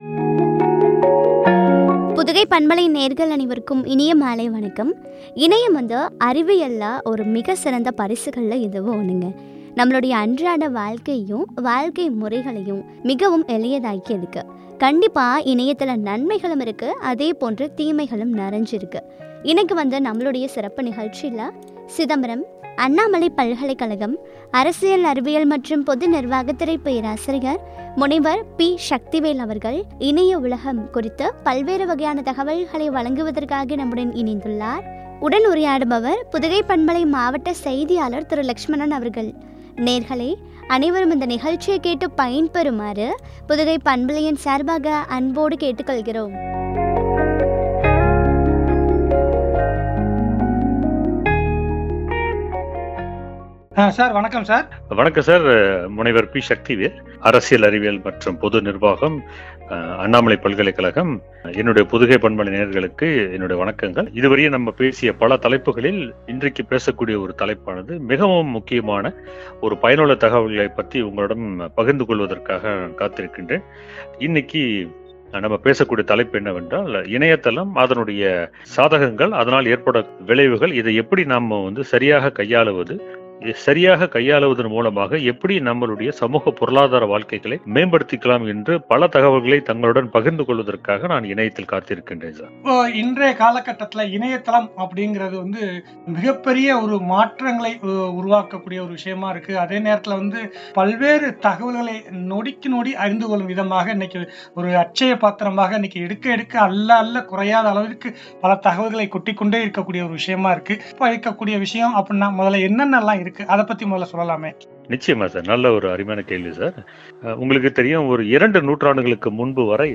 ” இணைய உலகம்” குறித்து வழங்கிய உரையாடல்.